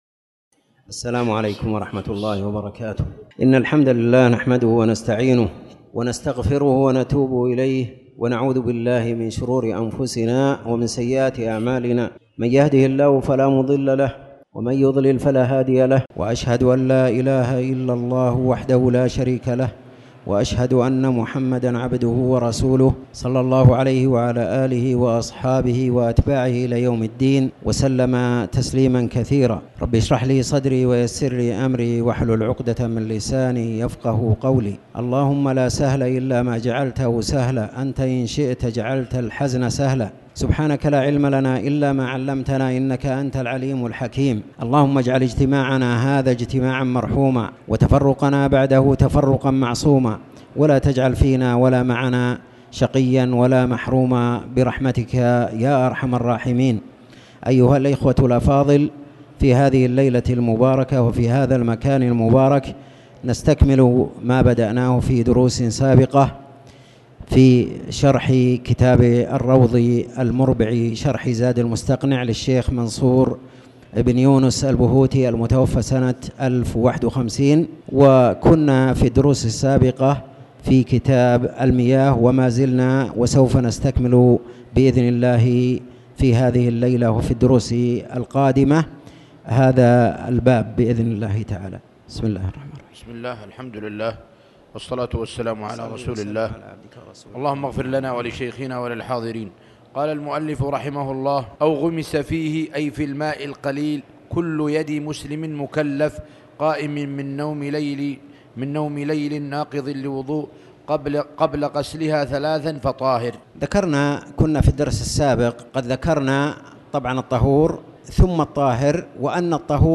تاريخ النشر ١٤ ربيع الثاني ١٤٣٩ هـ المكان: المسجد الحرام الشيخ